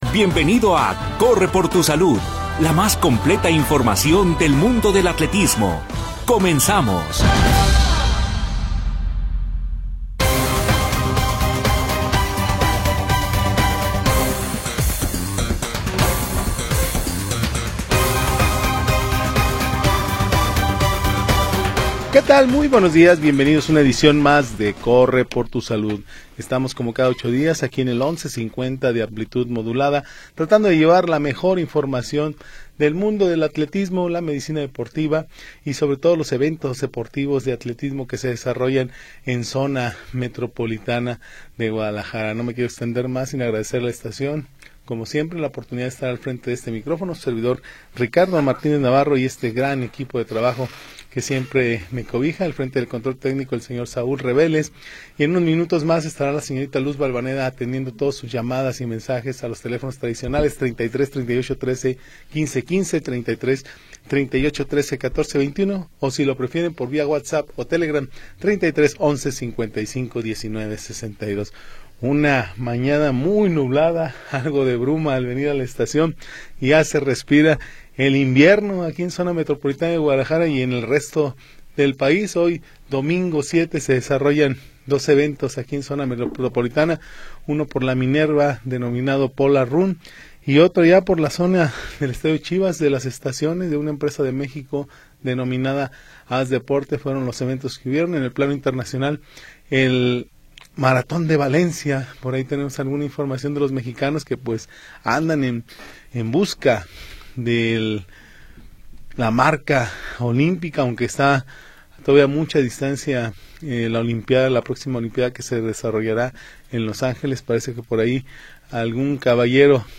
Atletismo, nutrición, ejercicio sin edad. Bajo la conducción del equipo de deportes Notisistema.
Programa transmitido el 7 de Diciembre de 2025.